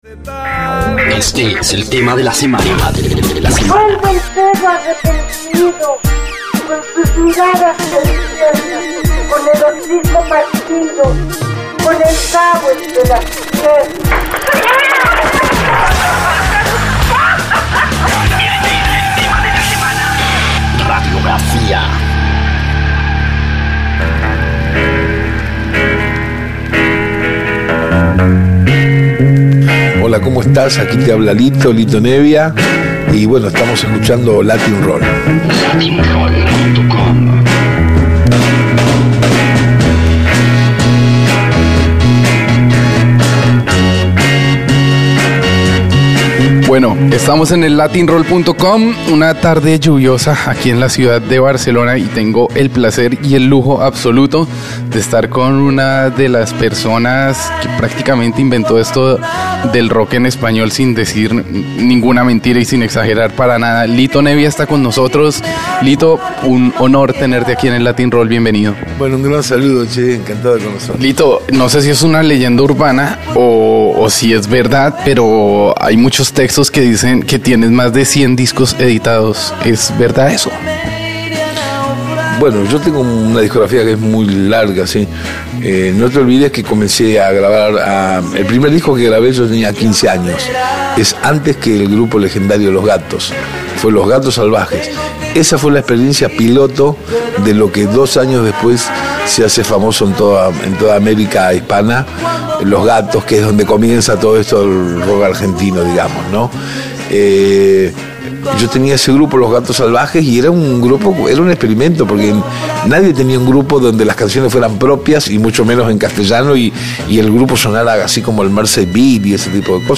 Latin-Roll - Entrevistas Litto Nebbia Reproducir episodio Pausar episodio Mute/Unmute Episode Rebobinar 10 segundos 1x Fast Forward 30 seconds 00:00 / Suscribir Compartir Feed RSS Compartir Enlace Incrustar